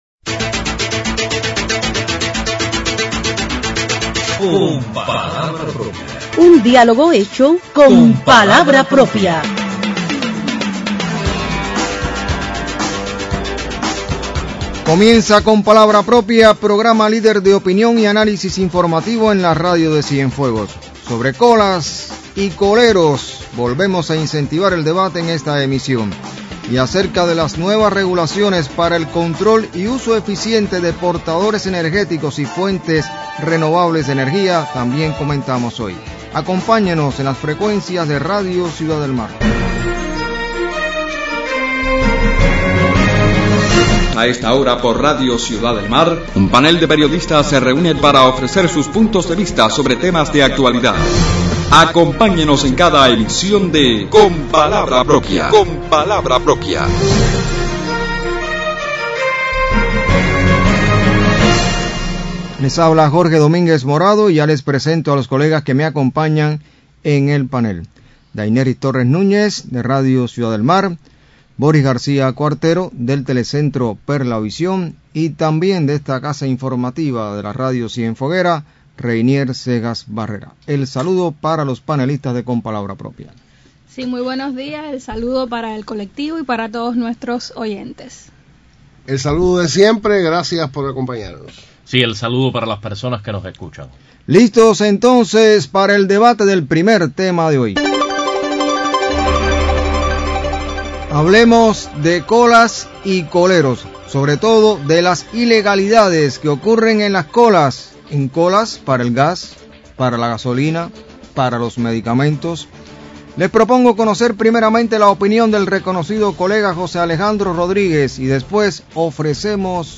Sobre las colas y los coleros debaten los panelistas del programa Con palabra propia en la emisión del 30 de noviembre de 2024.